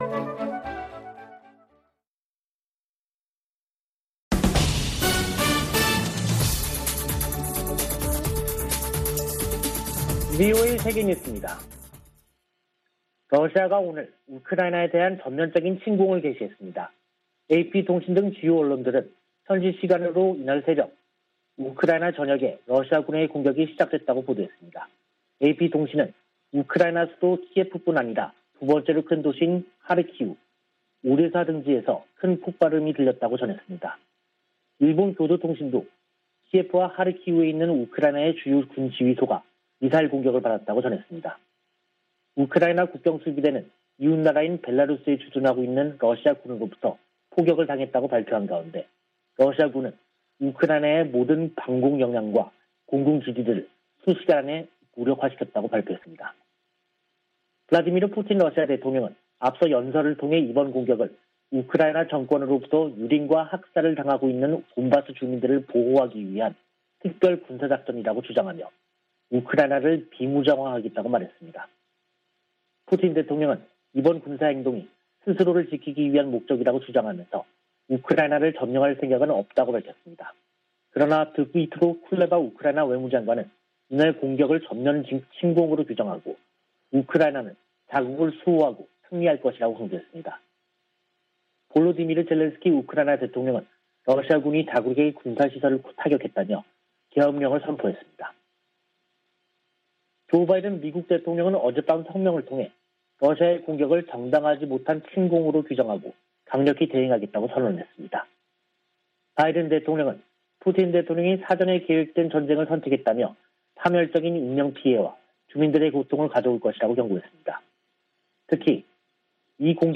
VOA 한국어 간판 뉴스 프로그램 '뉴스 투데이', 2022년 2월 24일 3부 방송입니다. 러시아의 우크라이나 침공으로 미-러 갈등이 격화되고 있는 가운데 북한의 외교 셈법이 복잡해졌다는 분석이 나오고 있습니다. 미 국방부는 우크라이나에 대한 한국의 지지 성명에 주목했다고 밝혔습니다. 미국의 전통적 대북 접근법으로는 북한 문제를 해결하는 데 한계가 있으며, 대통령의 리더십이 중요하다는 보고서가 나왔습니다.